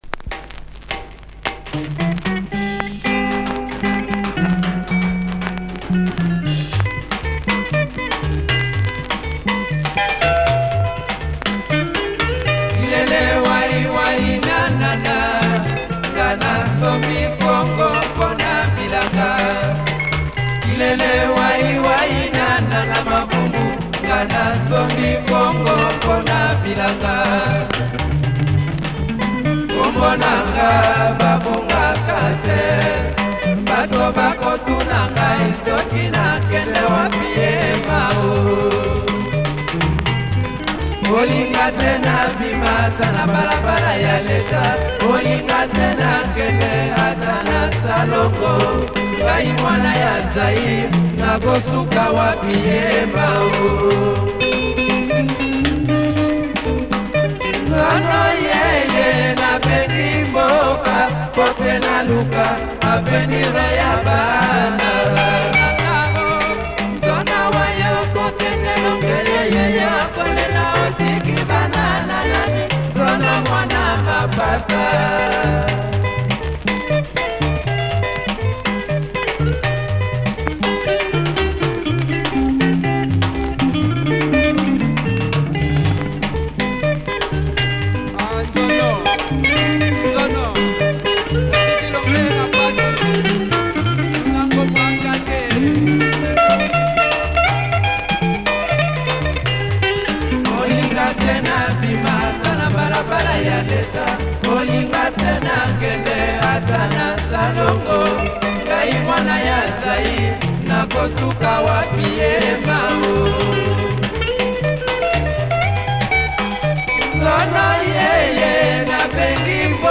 nice primitive stick drumming.